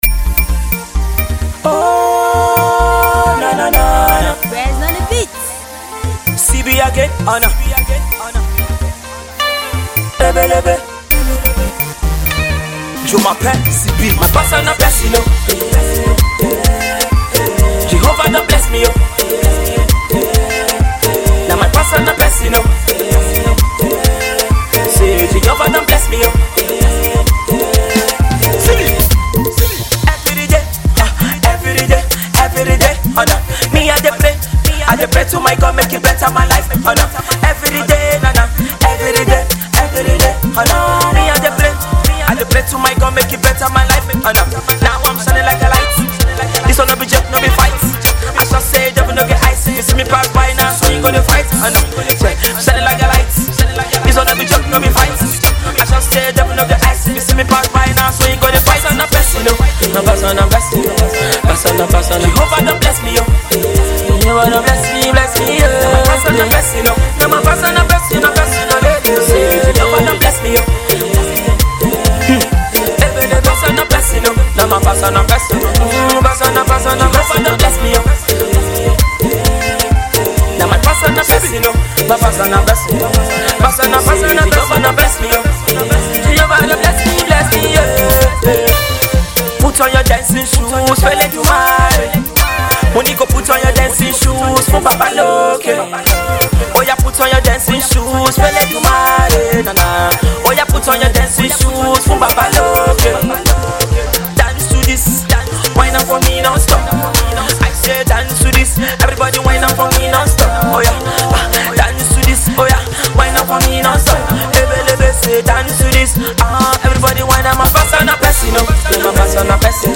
Listen and join in the dance!